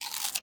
Minecraft Version Minecraft Version snapshot Latest Release | Latest Snapshot snapshot / assets / minecraft / sounds / mob / panda / eat3.ogg Compare With Compare With Latest Release | Latest Snapshot